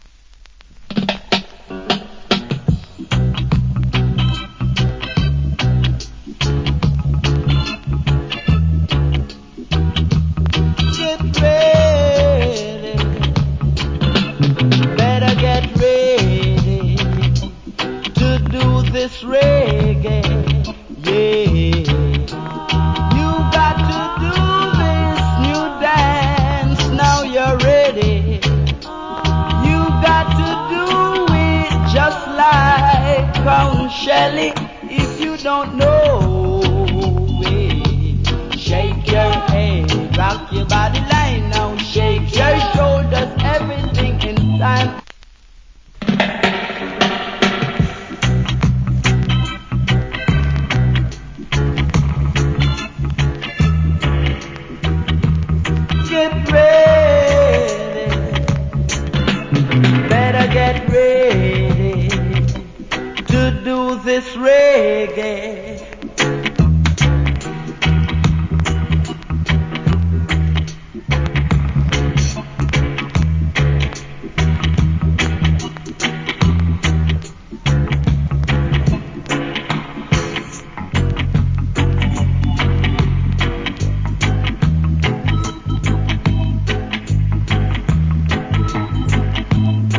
Cool Roots.